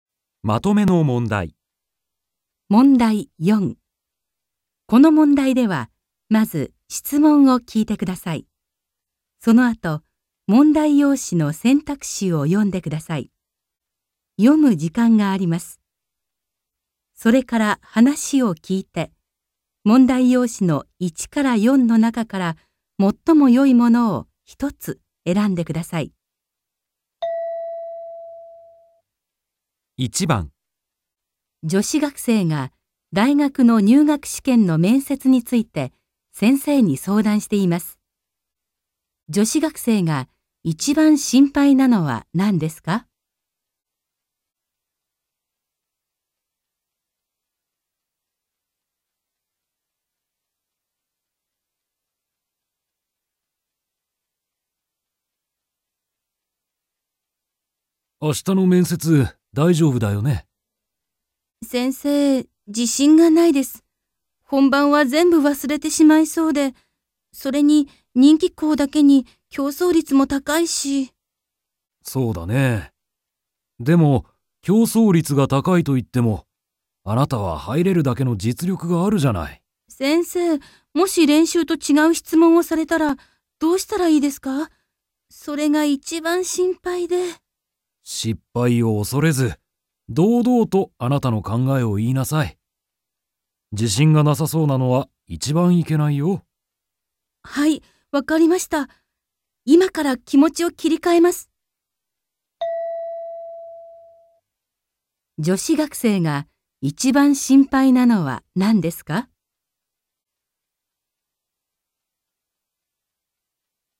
問題 4 ［聴解］